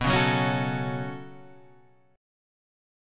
B7sus2 chord